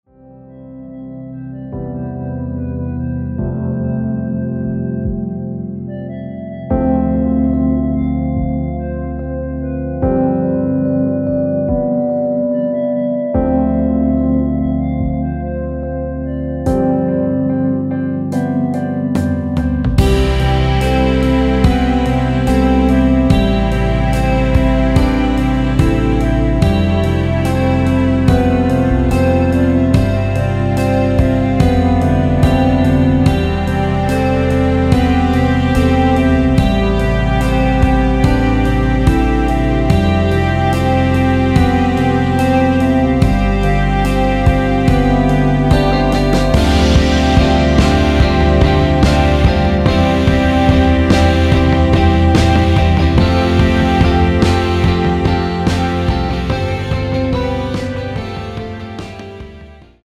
원곡 6분1초에서 4분 41초로 짧게 편곡된 MR입니다.
원키에서(-2)내린 멜로디 포함된(1절앞+후렴)으로 진행되는 MR입니다.
앞부분30초, 뒷부분30초씩 편집해서 올려 드리고 있습니다.
중간에 음이 끈어지고 다시 나오는 이유는